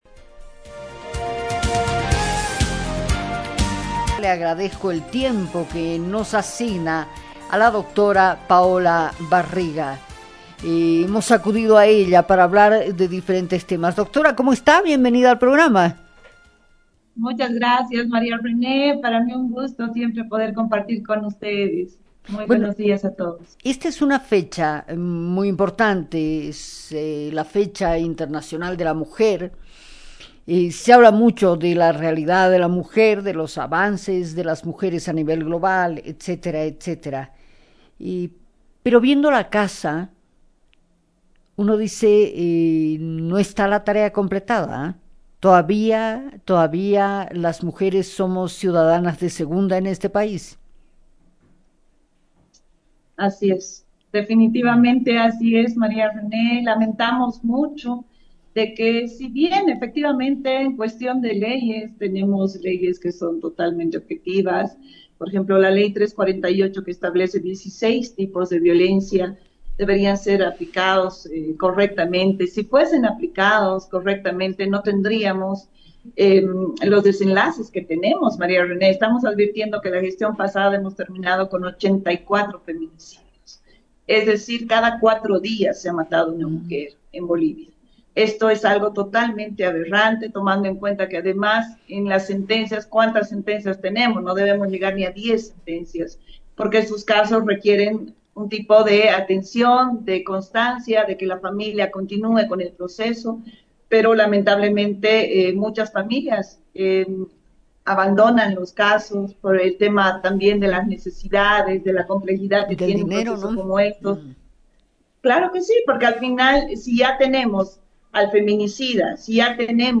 En este episodio, exploramos el significado del 8M y los desafíos actuales. Acompáñenos en esta conversación sobre la lucha, resistencia y futuro.